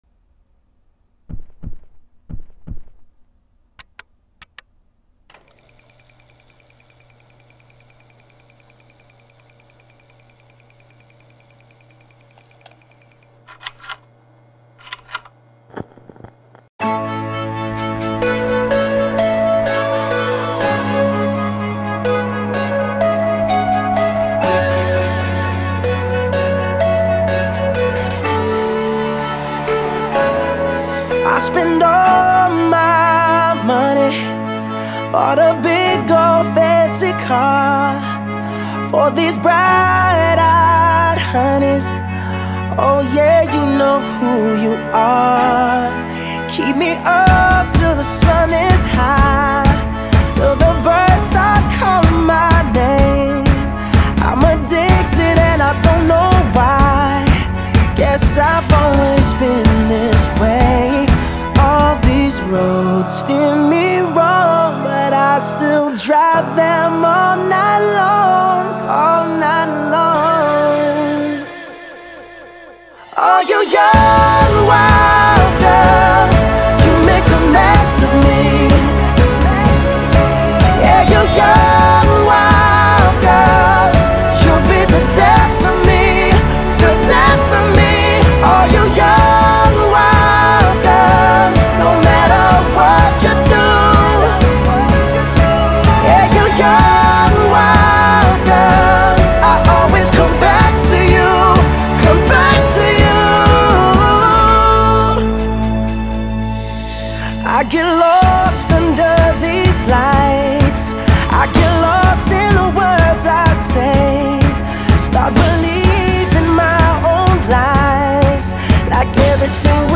Pop, Funk, R&B